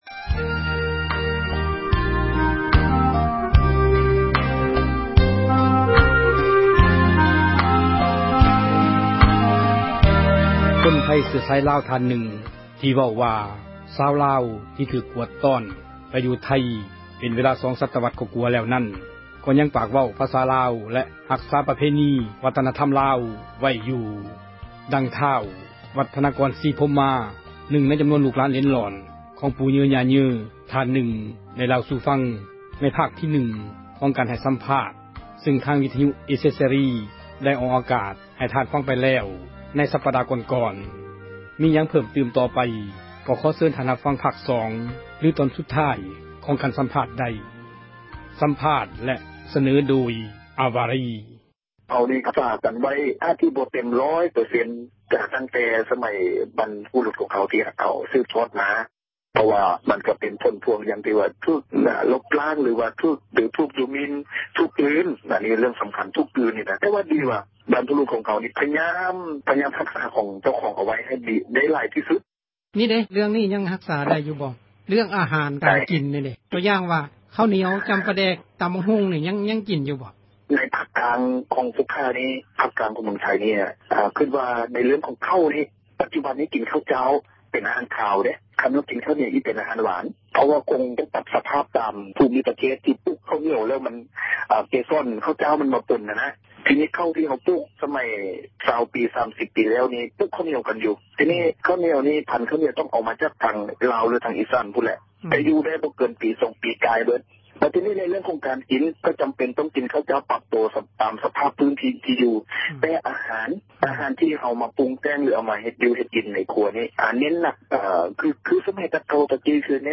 ສັມພາດຄົນໄທ ເຊື້ອສາຍລາວ(ຈົບ)